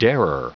Prononciation du mot darer en anglais (fichier audio)
Prononciation du mot : darer